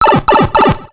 The Warp Pipe sound effect from the GCN version of Paper Mario: The Thousand-Year Door.
Pipe_PMTTYDGCN.wav